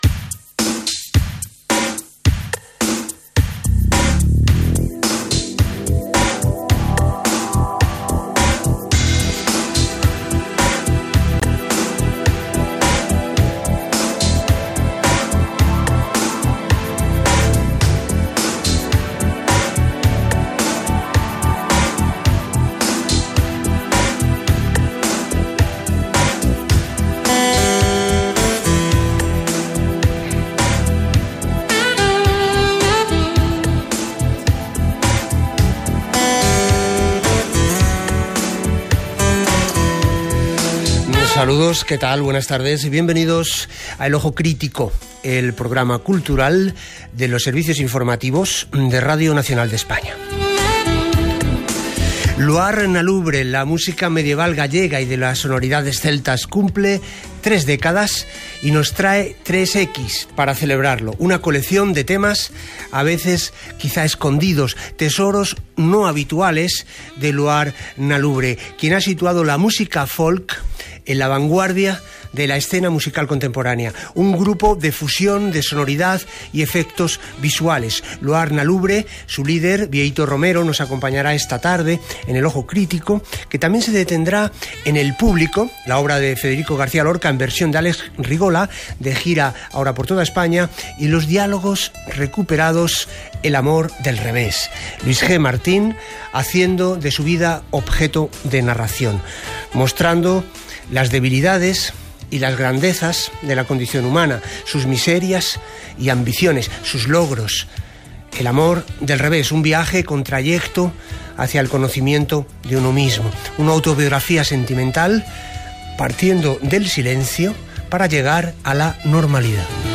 Sintonia, presentació, sumari de continguts, indicatiu